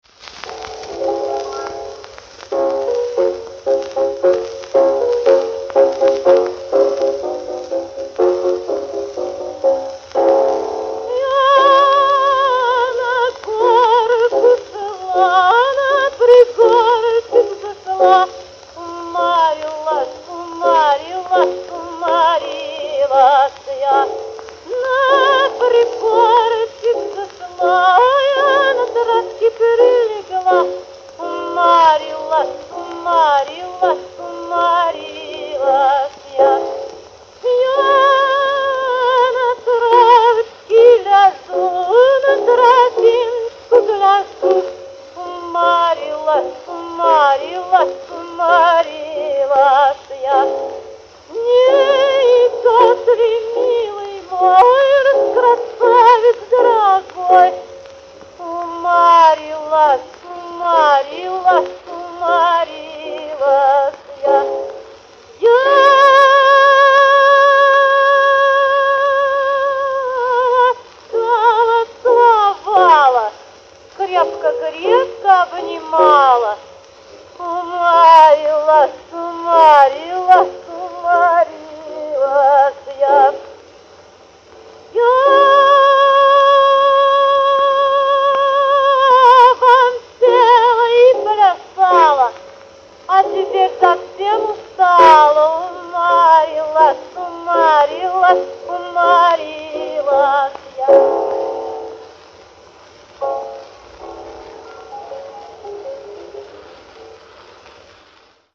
Русскую народную песню «Я на горку шла»
исполняет Анастасия Вяльцева